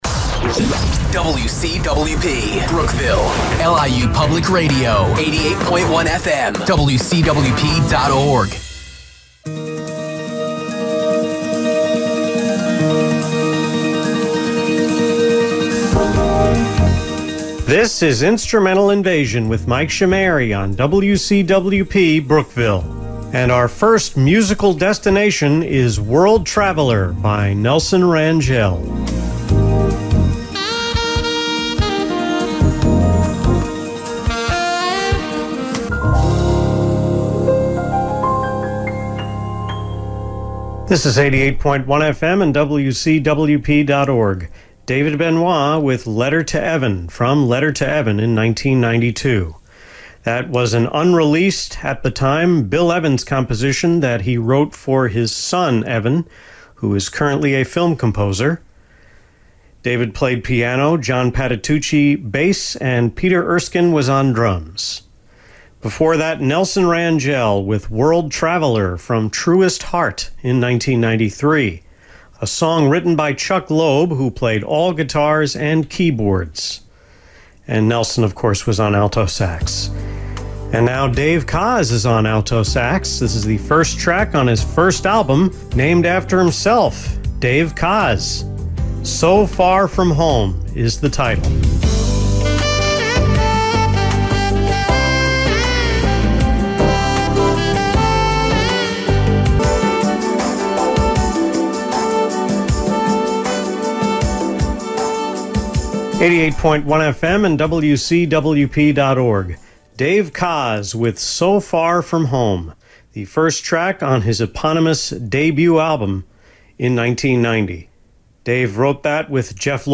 It was recorded over two days: the first hour on August 21 and the second on the 22nd.